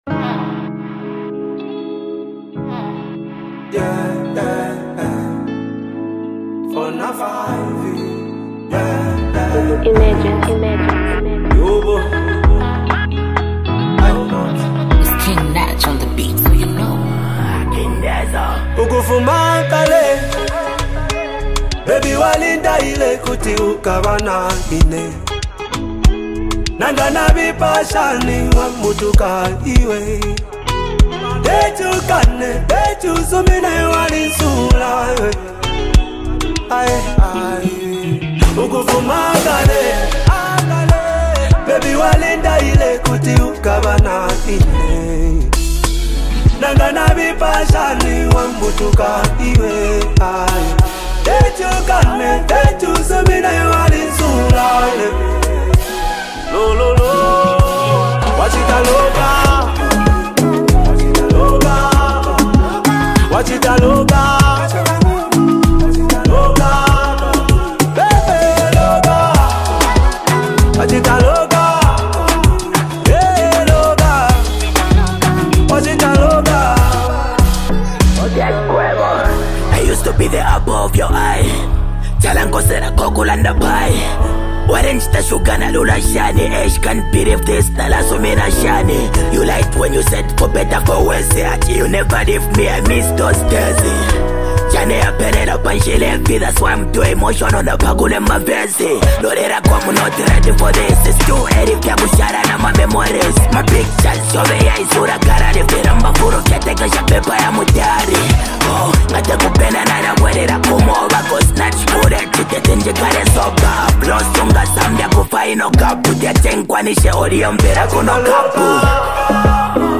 Zambian duo